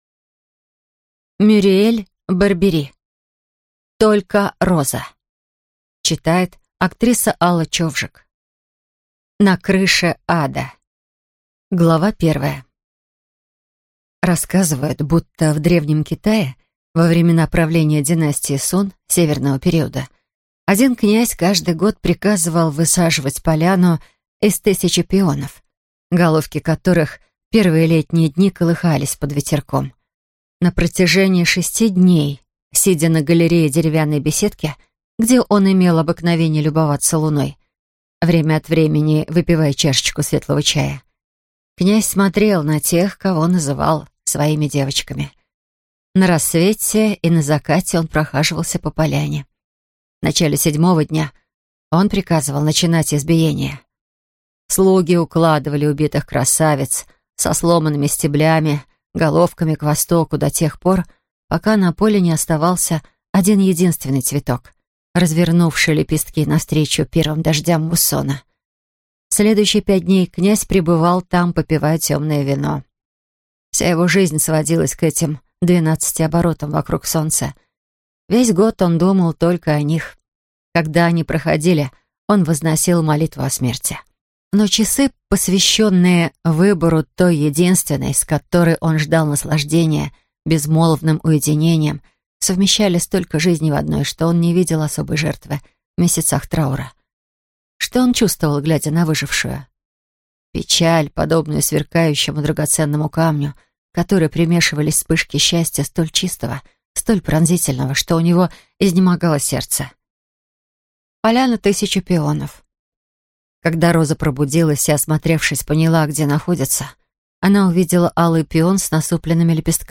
Аудиокнига Только роза | Библиотека аудиокниг